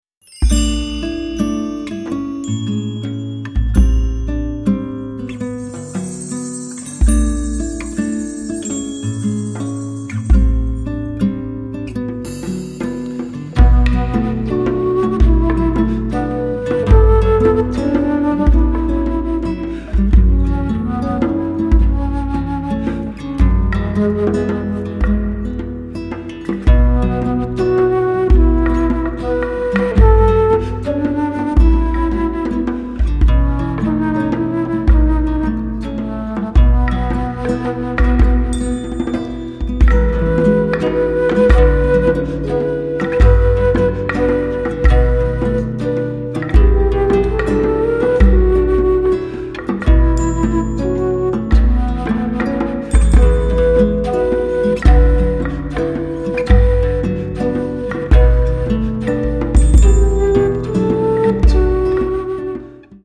at studio Voice
アルトフルート、ギター